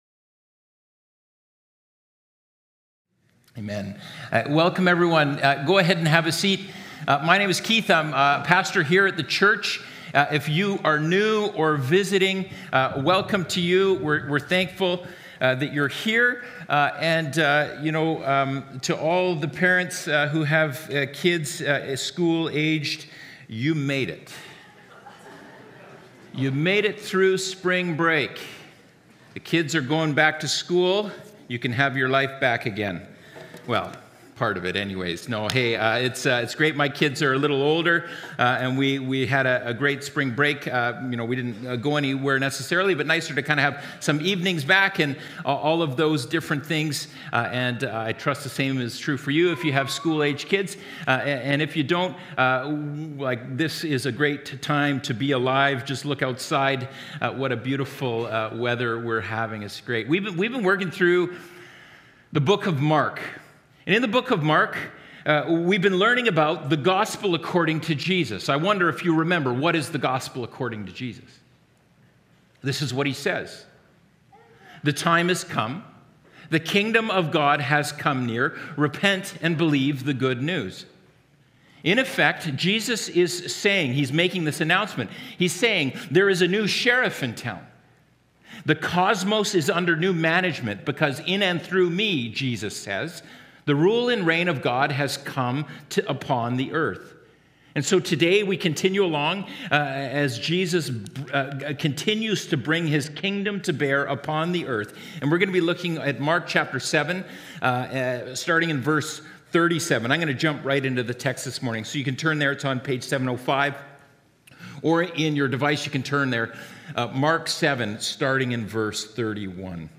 SERMONS | Mission Creek Alliance Church